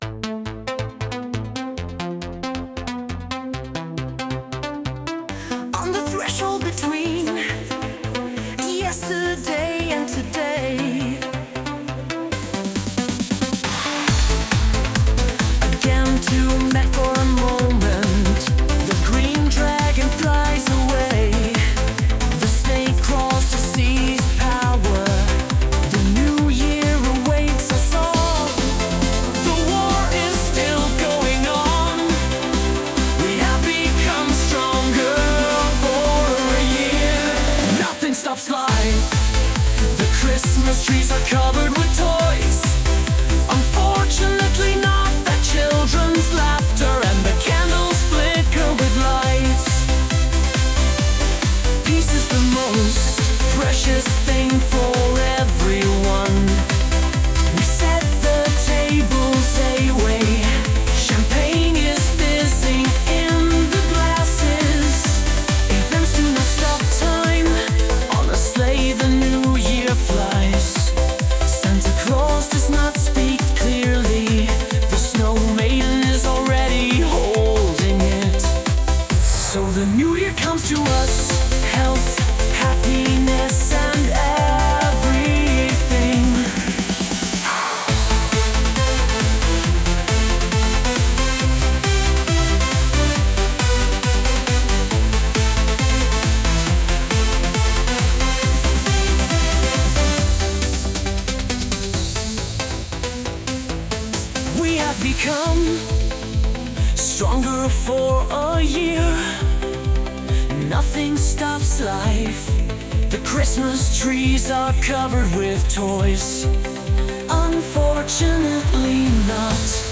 The song is the English version. The text is my own, the music and vocals are generated by artificial intelligence AI.